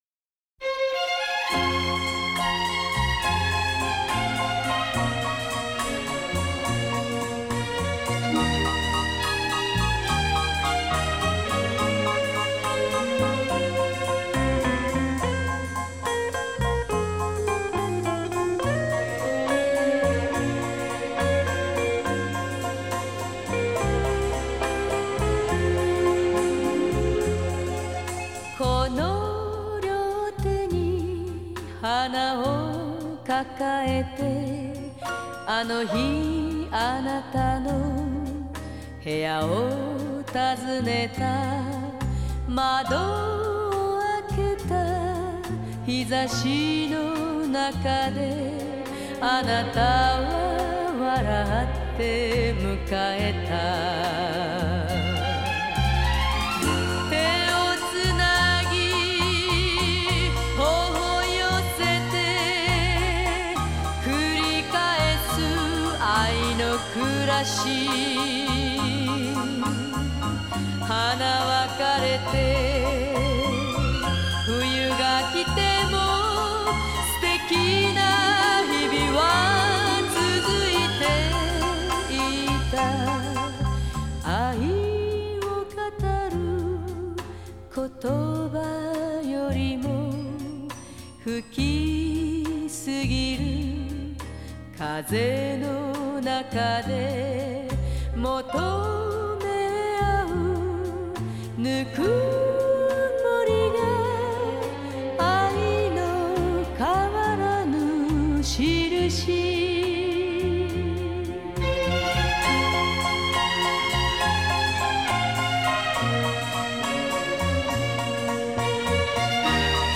Genre: Japanese Pop